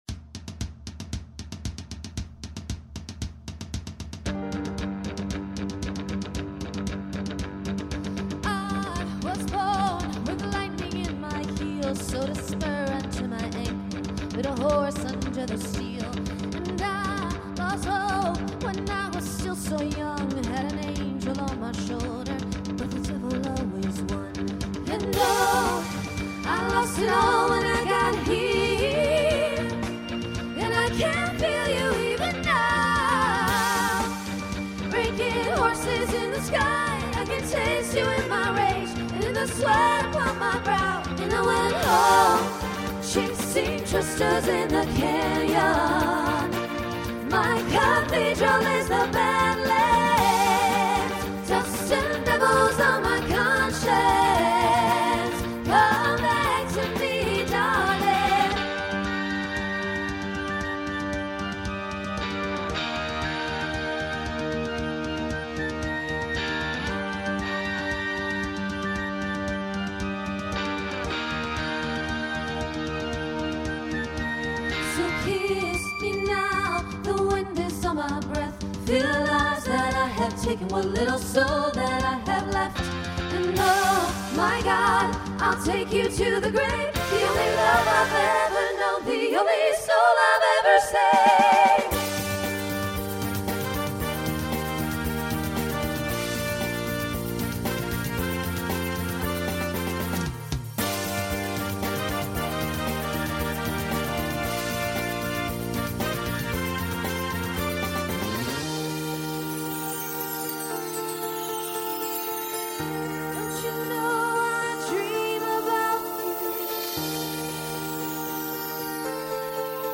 Voicing SSA Instrumental combo Genre Folk , Rock